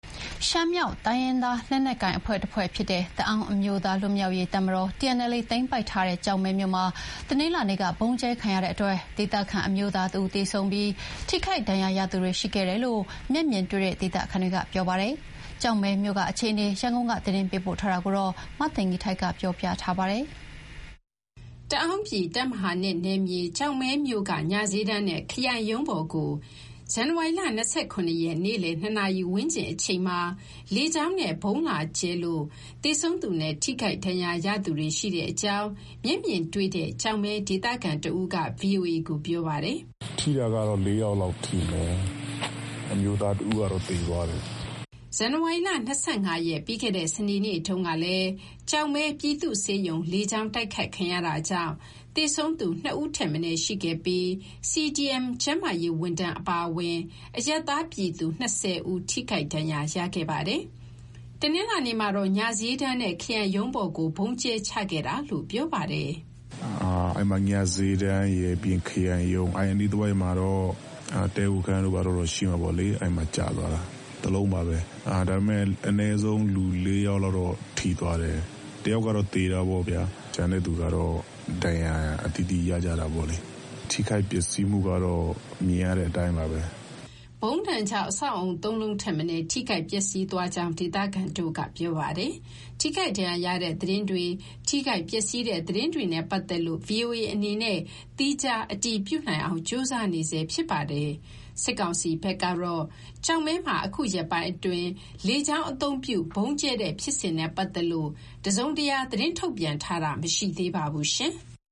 ရှမ်းမြောက် တိုင်းရင်းသားလက်နက်ကိုင်အဖွဲ့တဖွဲ့ဖြစ်တဲ့ တအာင်းအမျိုးသားလွတ်မြောက်ရေးတပ်မတော် TNLA သိမ်းပိုက်ထားတဲ့ ကျောက်မဲမြို့မှာ တနင်္လာနေ့က ဗုံးကြဲ ခံရတဲ့အတွက် ဒေသခံအမျိုးသား ၁ ဦးသေဆုံးပြီး ထိခိုက်ဒဏ်ရာရသူတွေ ရှိခဲ့တယ်လို့ မျက်မြင်တွေ့တဲ့ ဒေသခံတွေက ပြောပါတယ်။ ကျောက်မဲမြို့က အခြေအနေ ရန်ကုန်ကနေ သတင်းပေးပို့ထားပါတယ်။
တအာင်းပြည်တပ်မဟာ ၂ နယ်မြေ ကျောက်မဲမြို့က ညဈေးတန်းနဲ့ ခရိုင်ရုံးပေါ်ကို ဇန်နဝါရီလ ၂၇ ရက်၊ နေ့လည် ၂ နာရီဝန်းကျင်အချိန်မှာ လေကြောင်းနဲ့ ဗုံးလာကြဲလို့ သေဆုံးသူနဲ့ ထိခိုက်ဒဏ်ရာရသူတွေ ရှိတဲ့အကြောင်း မျက်မြင်တွေ့တဲ့ ကျောက်မဲဒေသခံတဦးက ဗွီအိုအေကို ပြောပါတယ်။